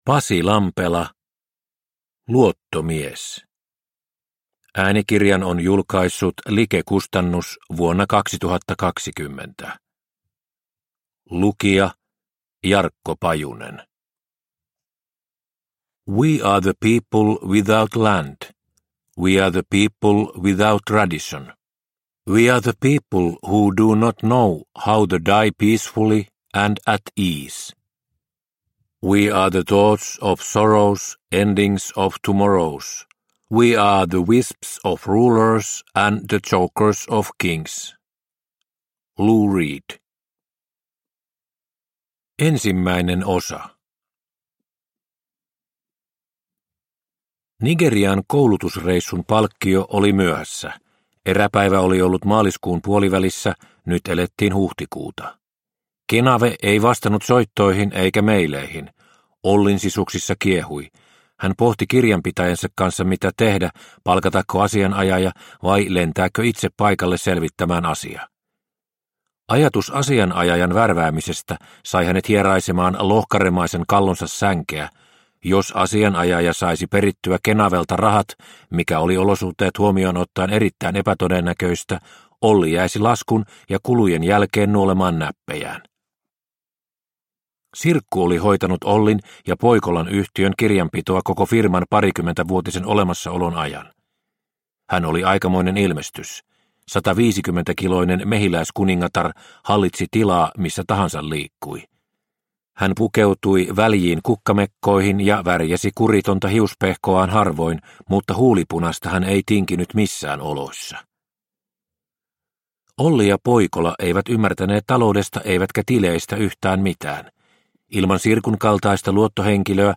Luottomies – Ljudbok – Laddas ner